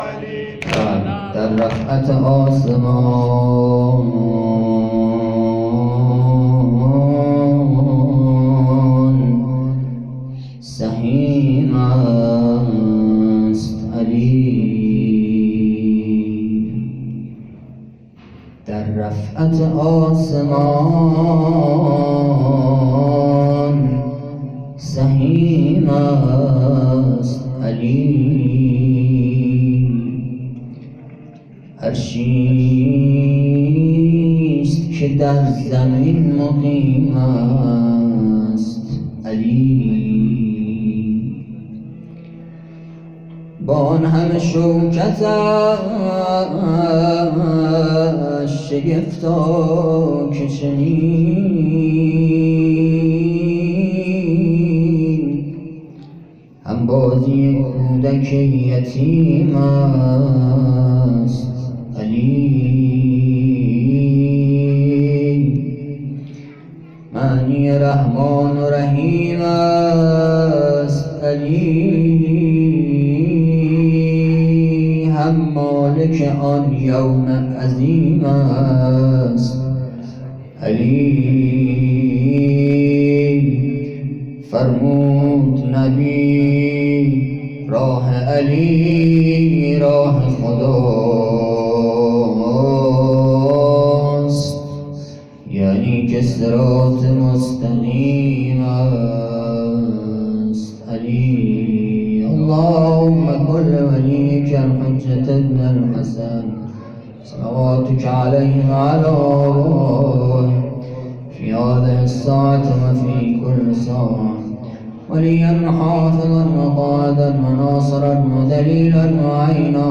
شبهای قدر
شعر پایانی